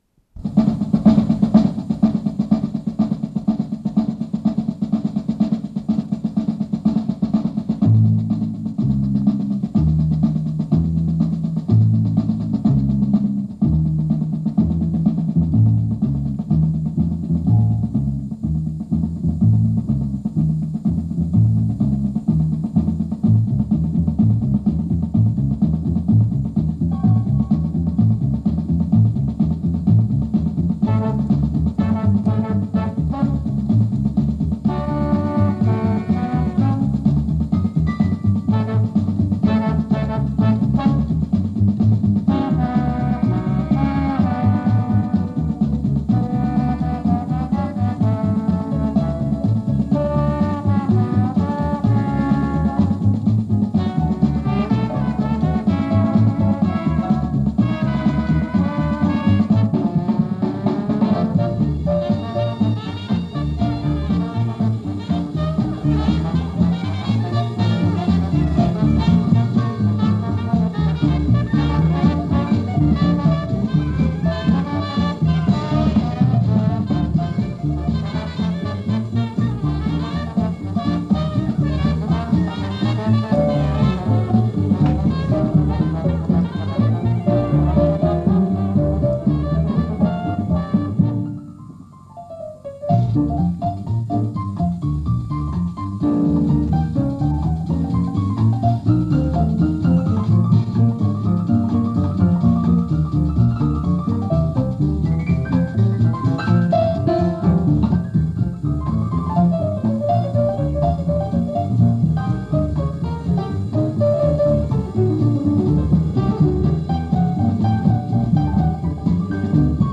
Диксиленд
труба
кларнет
тромбон
рояль
ударные
контрабас